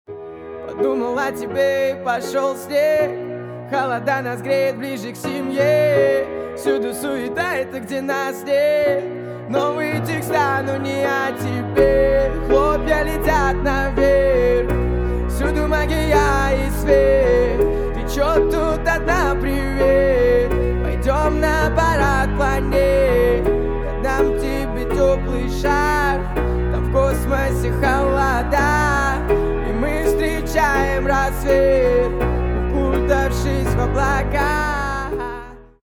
• Качество: 128, Stereo
поп
спокойные
красивая мелодия
инструментальные
пианино
Начало трека, приятная мелодия